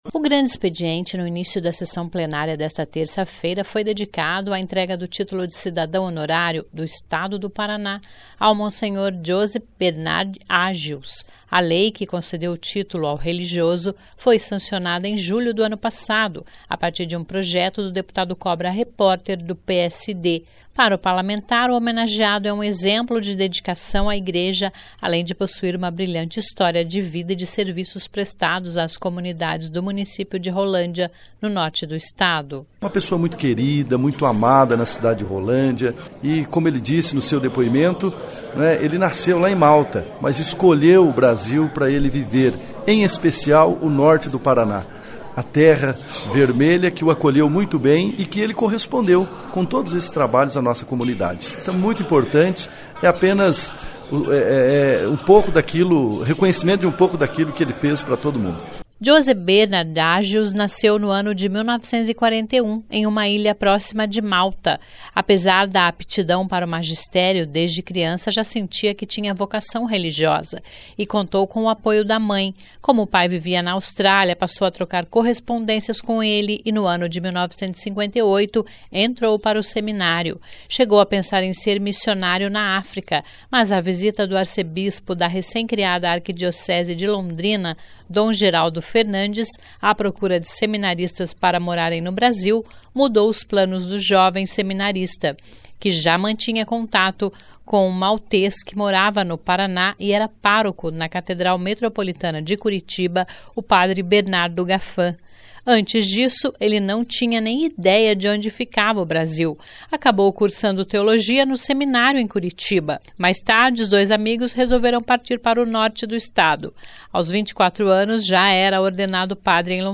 (Sonora)